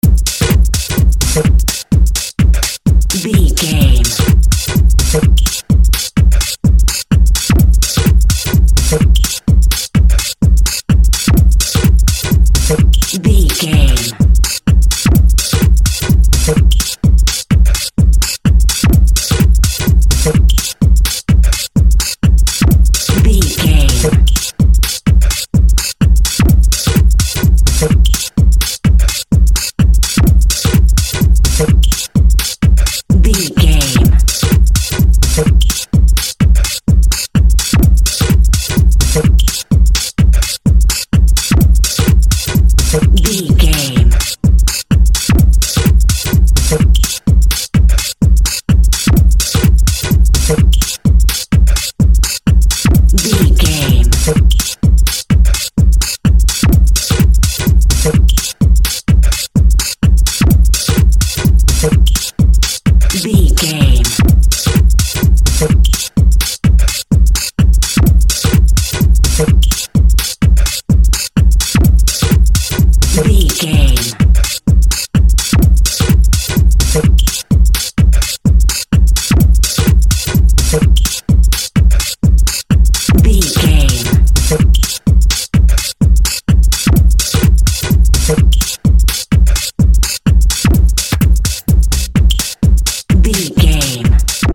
Epic / Action
Fast paced
Atonal
intense
futuristic
energetic
driving
repetitive
dark
synthesiser
drum machine
electro house
progressive house
synth lead
synth bass